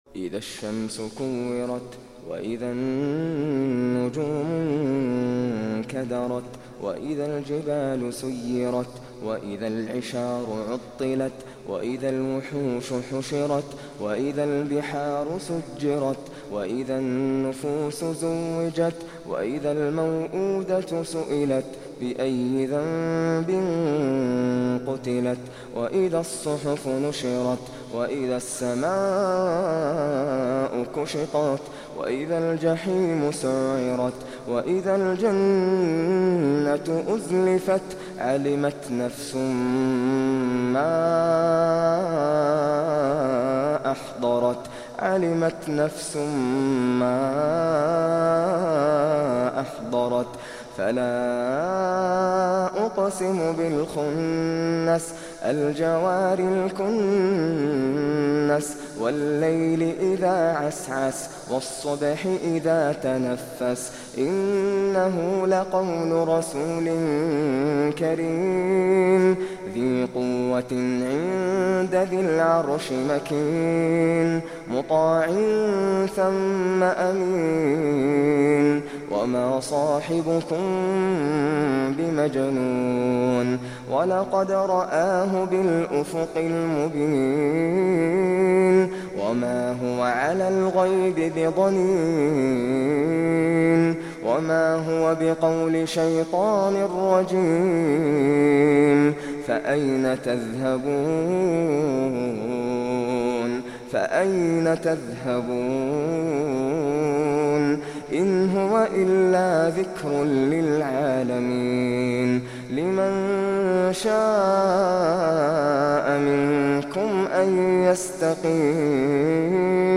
Surah Repeating تكرار السورة Download Surah حمّل السورة Reciting Murattalah Audio for 81. Surah At-Takw�r سورة التكوير N.B *Surah Includes Al-Basmalah Reciters Sequents تتابع التلاوات Reciters Repeats تكرار التلاوات